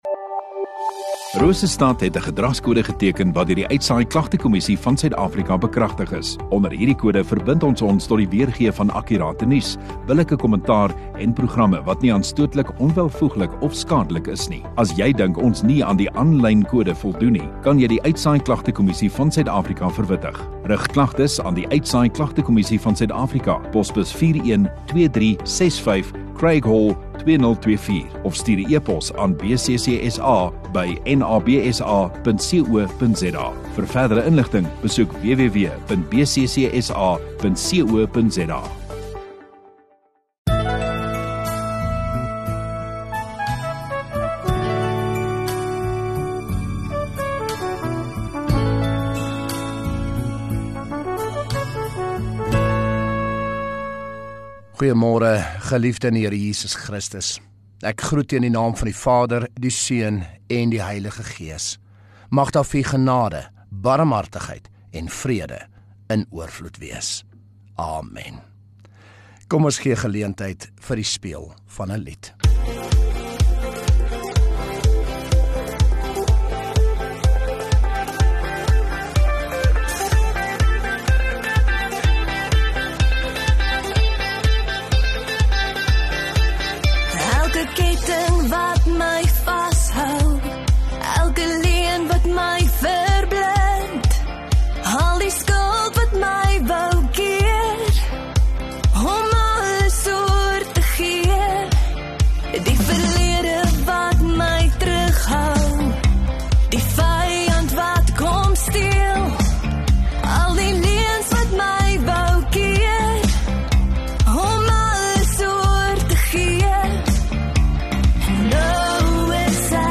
23 Mar Sondagoggend Erediens